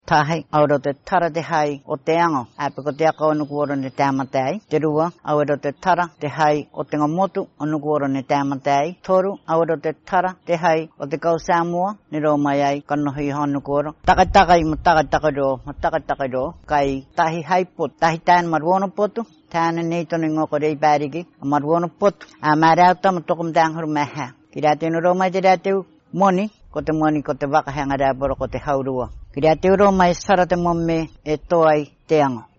I keep hearing the nasalized word “highñ” which suggests to me Hindi but it couldn’t be that easy.
This is the language spoken by this woman.